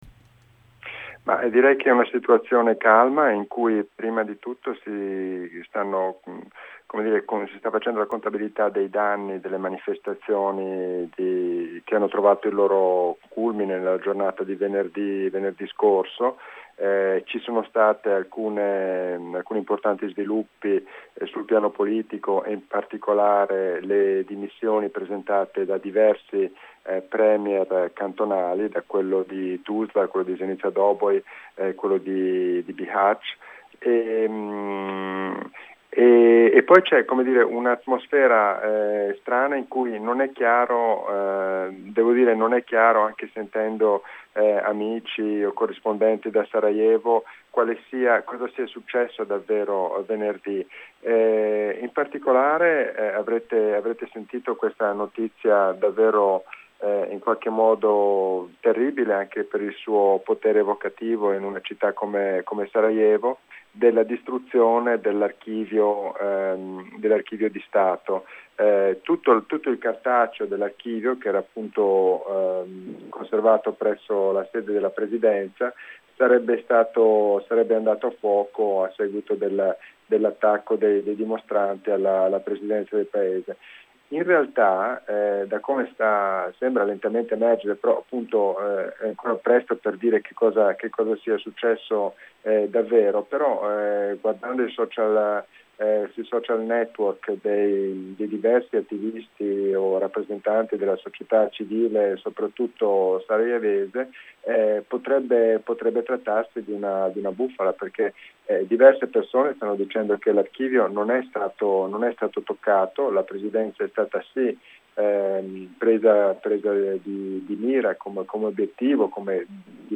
Proseguono in Bosnia le proteste anticrisi e austerity, con tre giorni di violente manifestazioni in diverse città. La corrispondenza per Radio Onda D'Urto